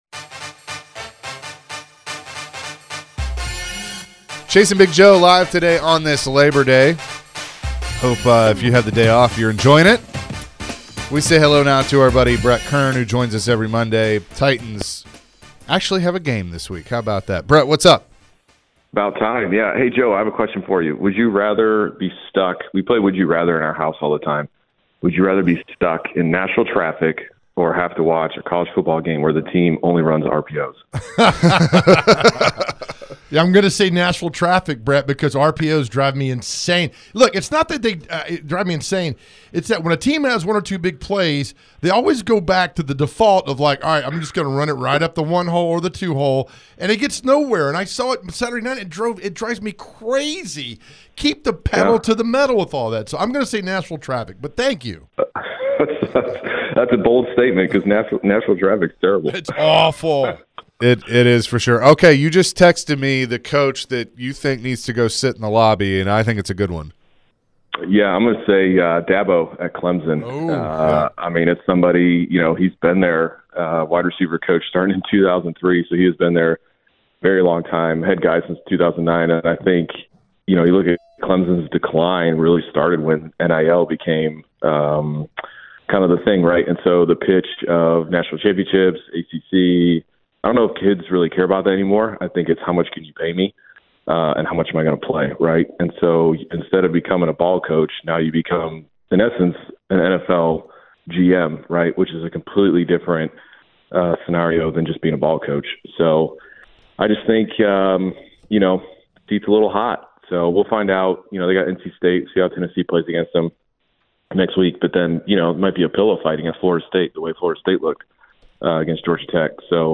Former Titans Punter Brett Kern joined the show and shared his thoughts on which college coaches are on the hot seat.